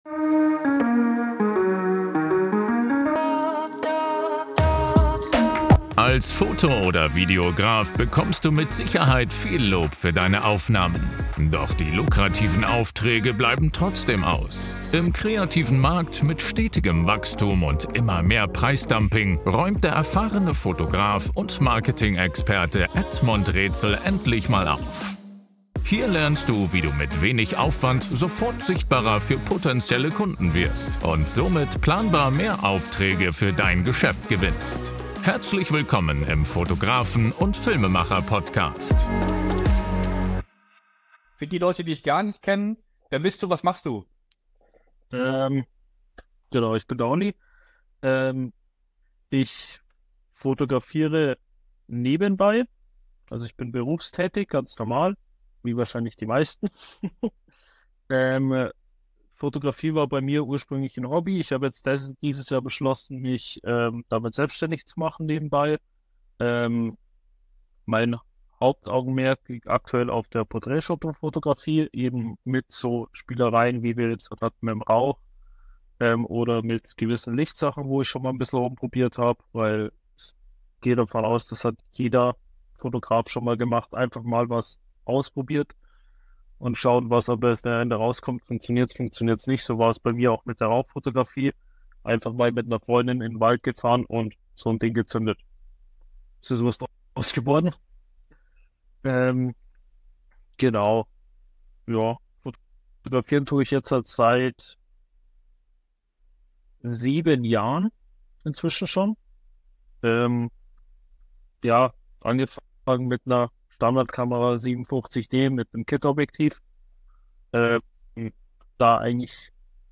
In diesem spannenden Interview erzählt er über seinen Weg, über spannende Anfänge, welche Bereiche der Fotografie er bereits getestet hat und warum in ihm der Wunsch wuchs mehr aus dem Hobby zu machen.